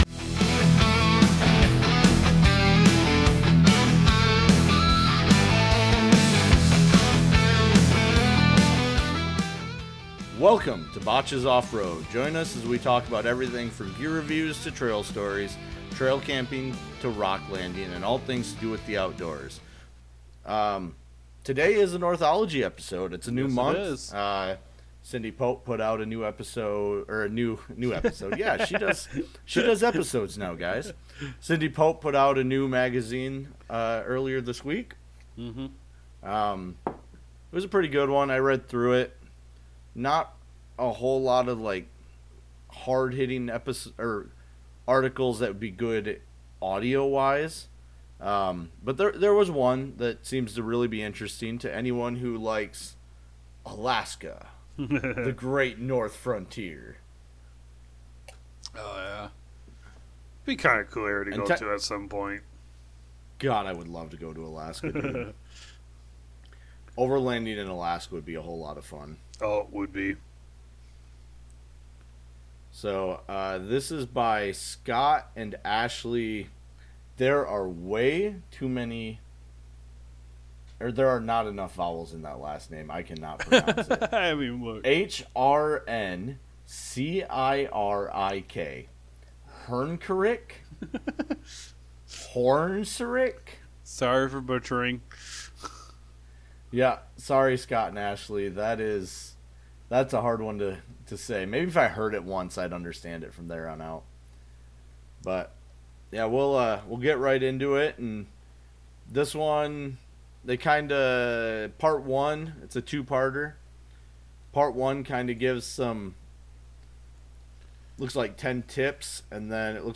in this episode we read soem articles from the fantastic Northology Magazine